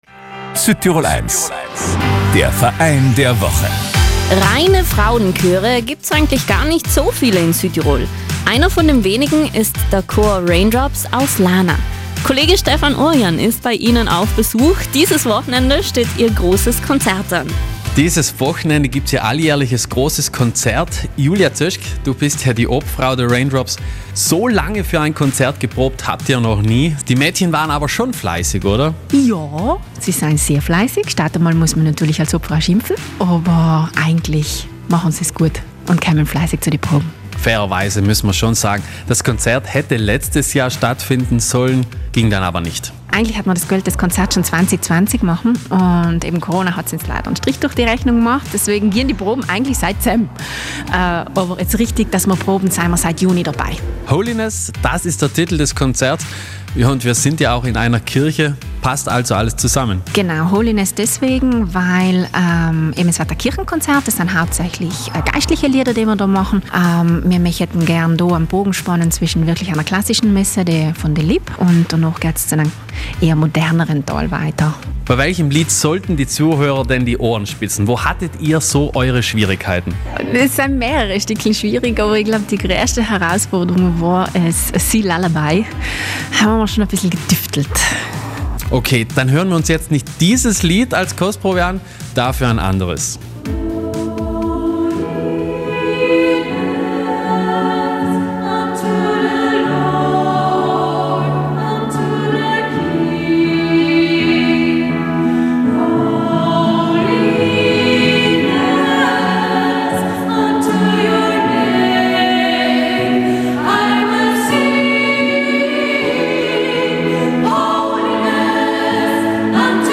„Raindrops" nennt sich der Mädchenchor aus Lana. Seit 20 Jahren schon trifft sich der Chor einmal in der Woche zum Proben.